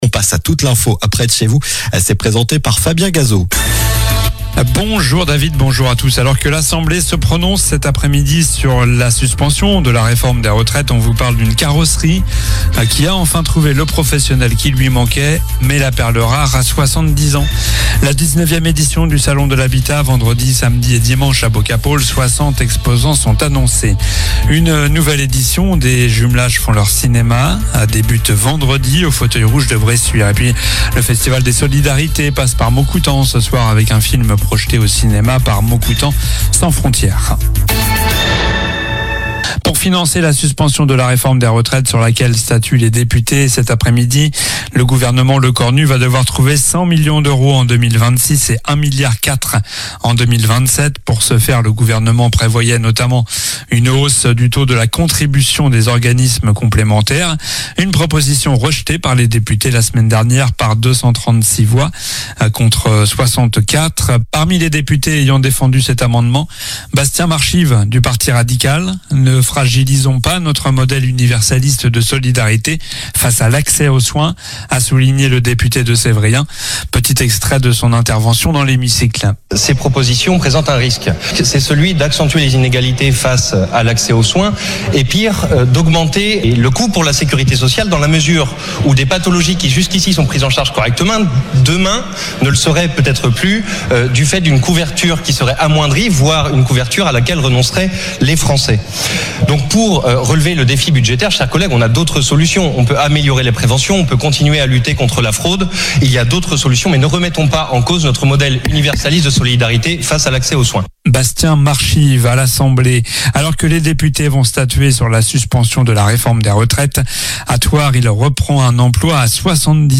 Journal du mercredi 12 novembre (midi)